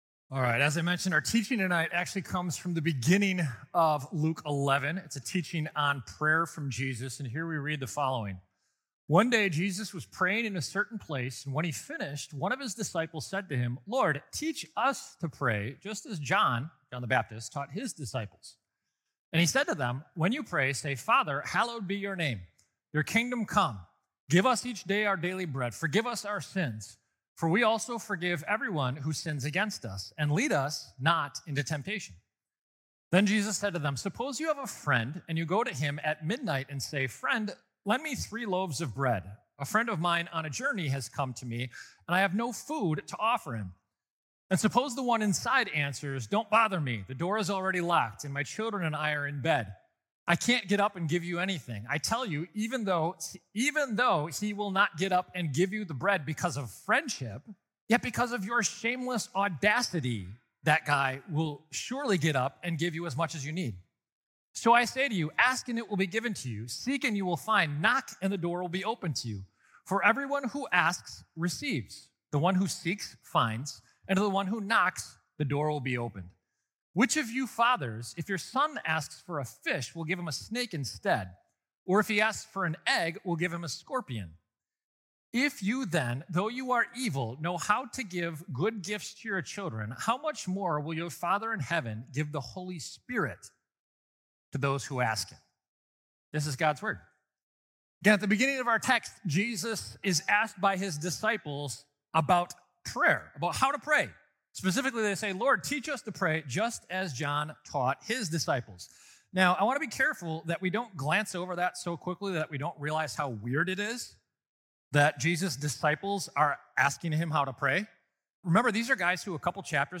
Weekly Sermons from St. Marcus Lutheran Church, Milwaukee, Wisconsin